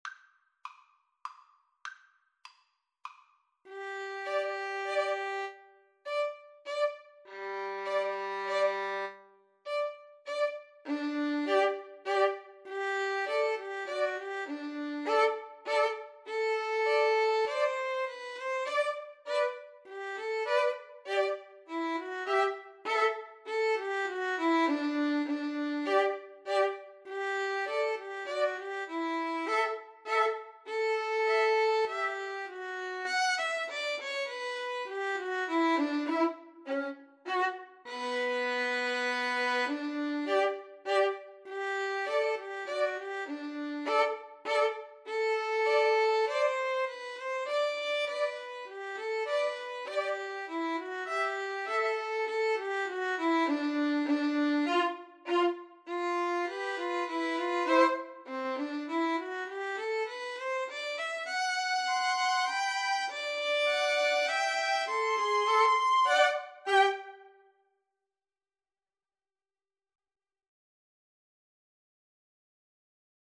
3/4 (View more 3/4 Music)
Slowly = c.100
Classical (View more Classical Violin Trio Music)